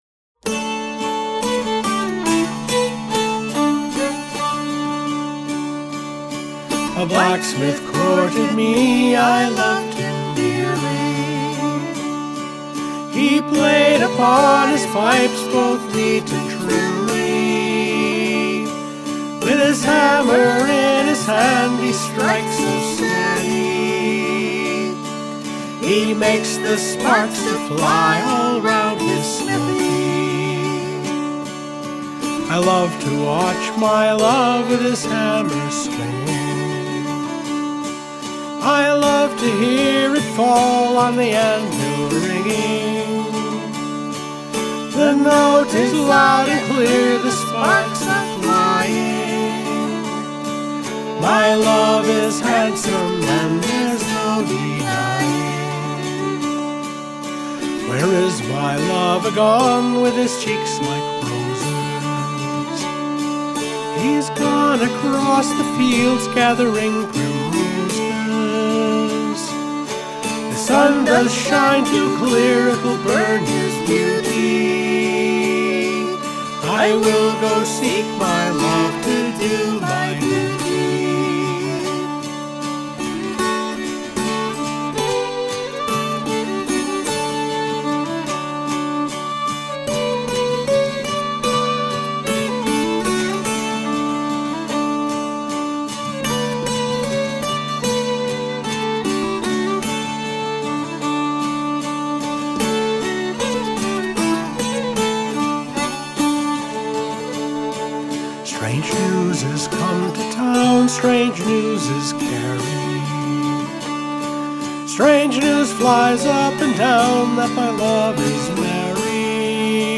An English song.